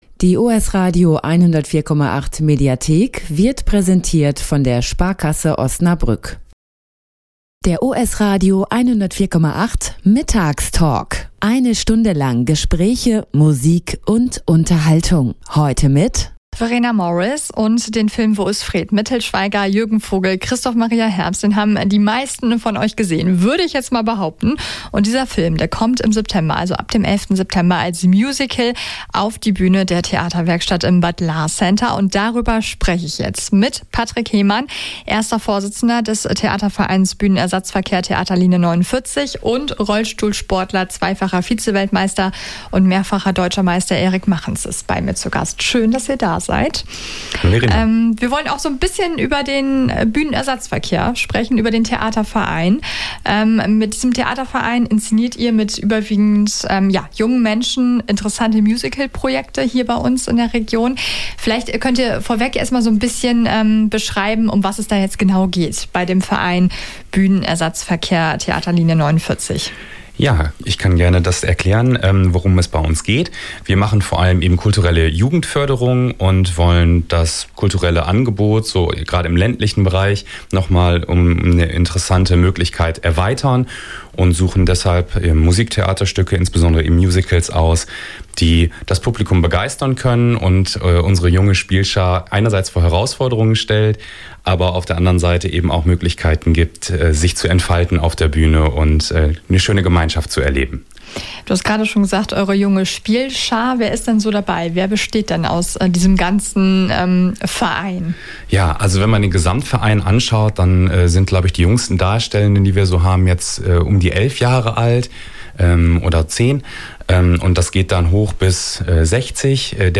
Hier ist der Mitschnitt des ganzen Interviews: